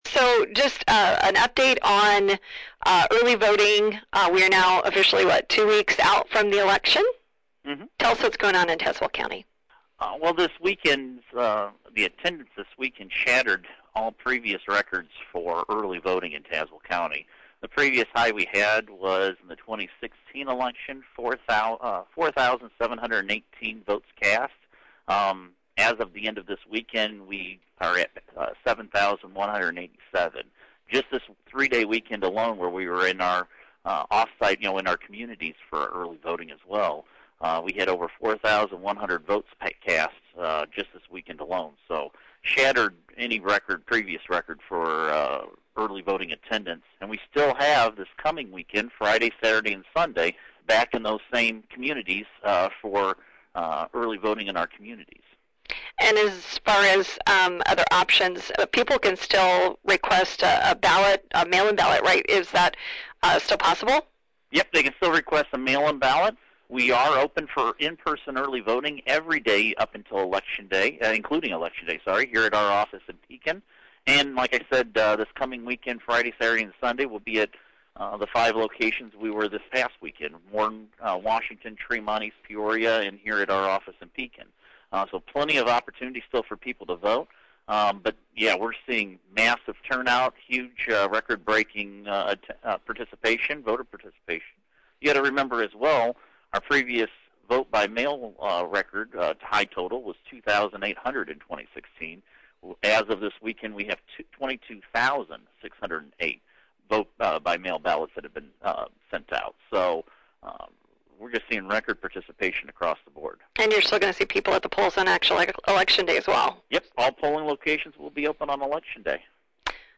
Here’s the full interview with John Ackerman.